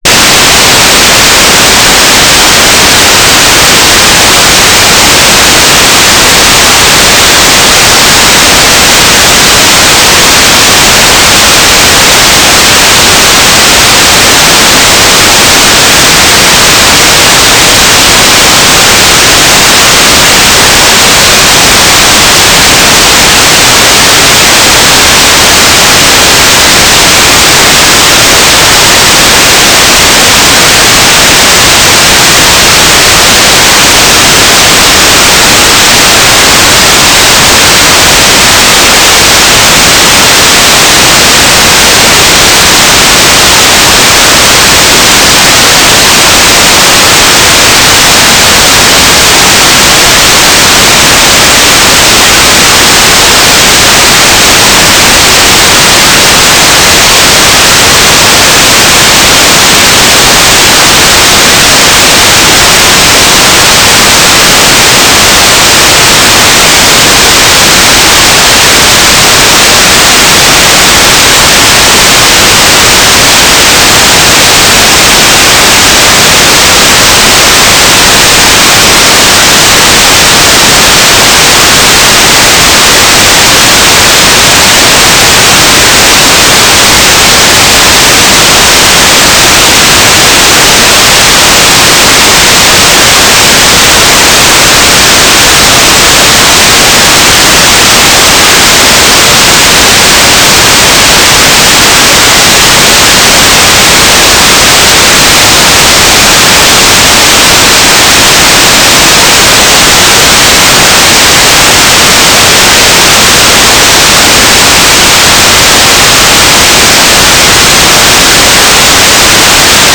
"transmitter_mode": "FSK",